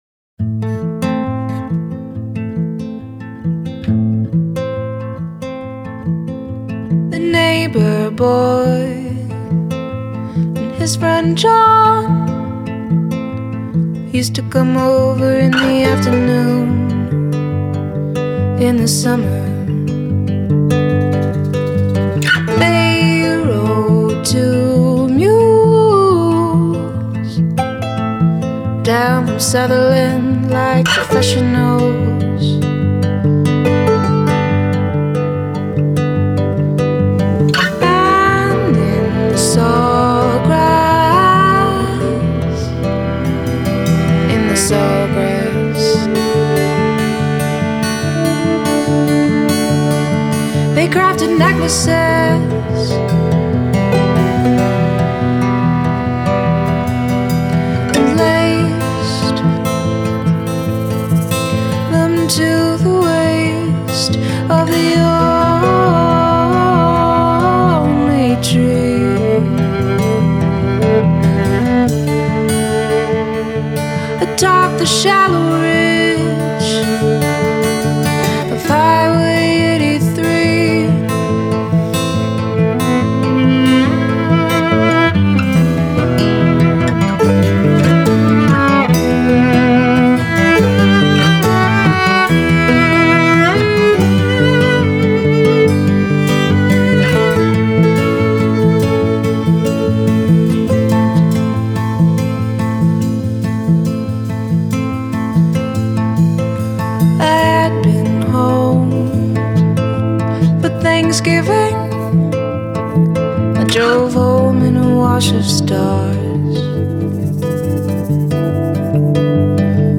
folksy album